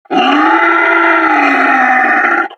Mercenary_Park_Yeti_statue_growl5.wav